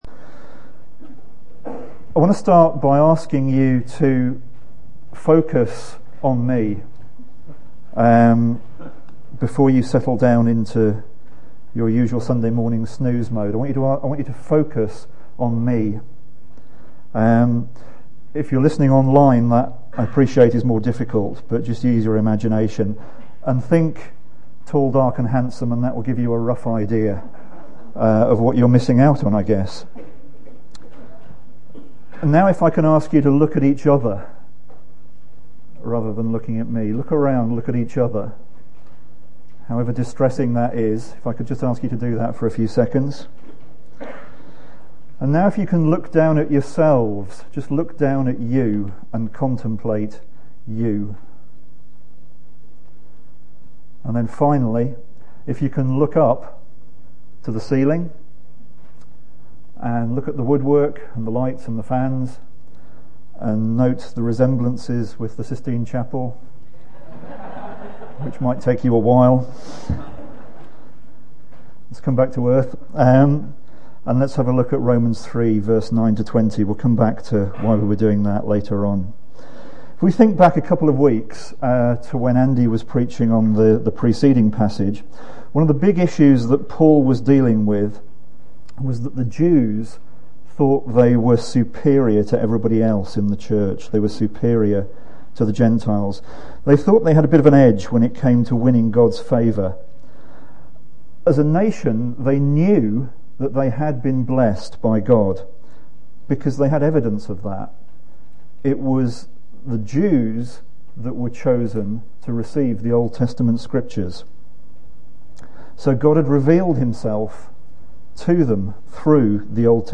No-one.... (Romans 3:9-20) from the series Good News for All. Recorded at Woodstock Road Baptist Church on 06 November 2011.